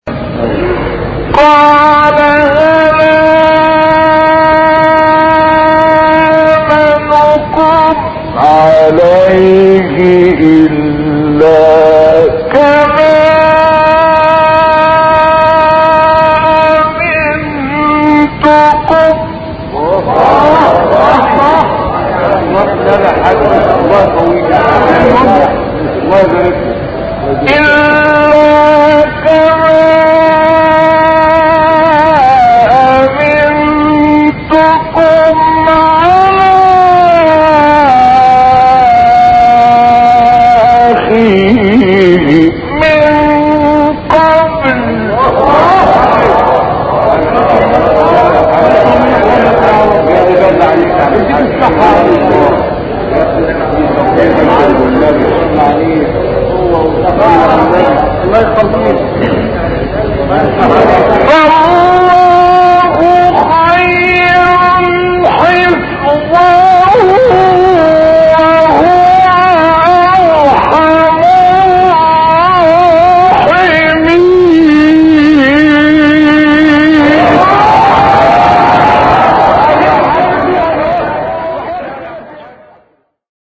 ۳ تلاوت کمیاب از استاد «عبدالفتاح شعشاعی» + دانلود/ تلاوتی با نفَس کوتاه
در ادامه سه قطعه از تلاوت‌های استاد شعشاعی ارائه می‌شود.